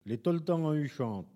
Collectif-Patois (atlas linguistique n°52)
Catégorie Locution